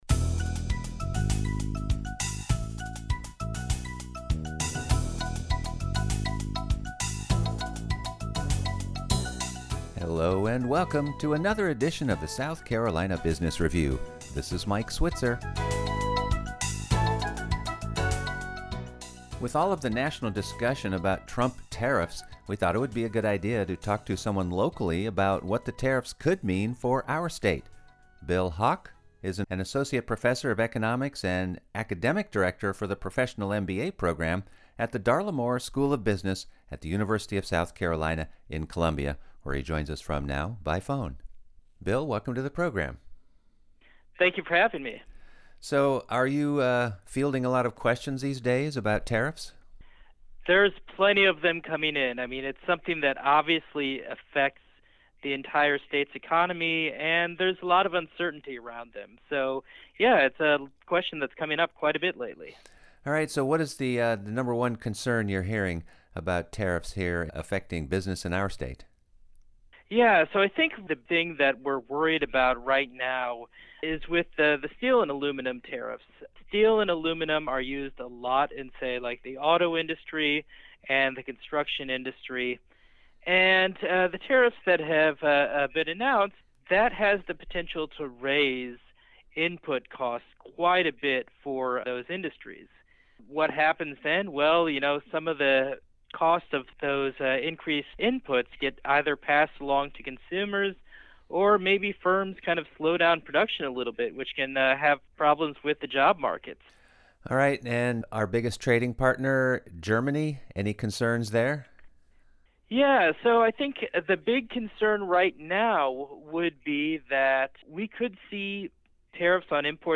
focuses on news from South Carolina's business community with interviews of many small business owners and business leaders from around the state. South Carolina's nonprofits, including its colleges and universities are also regularly featured on the program, as well as many of the state's small business support organizations.